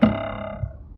mcl_bows_hit_wiggle.ogg